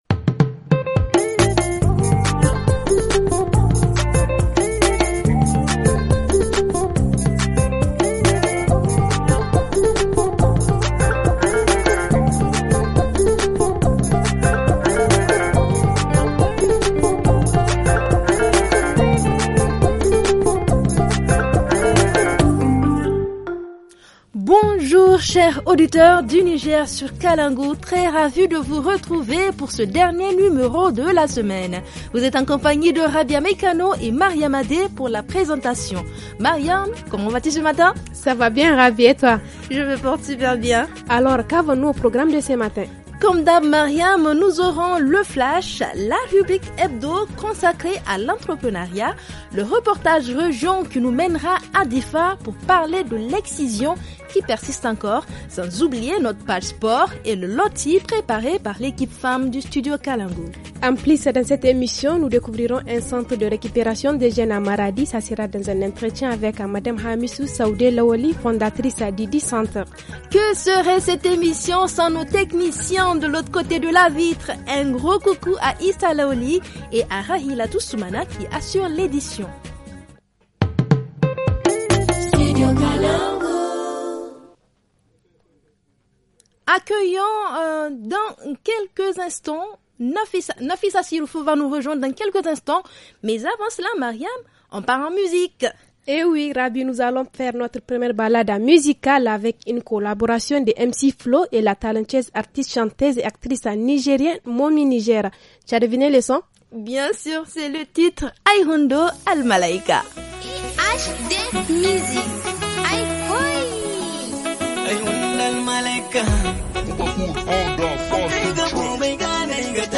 -Rubrique hebdo, Entrepreneuriat : Production et vente de produits bio par les femmes Hadin kai de Tsarnawa ;-Reportage région : Des jeunes victimes d’excision à N’Guigmi ;-Playlist-musique :